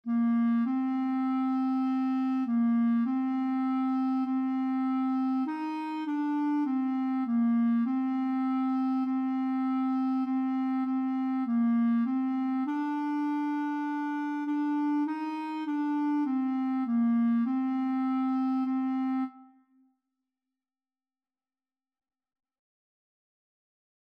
4/4 (View more 4/4 Music)
Bb4-Eb5
Clarinet  (View more Beginners Clarinet Music)
Classical (View more Classical Clarinet Music)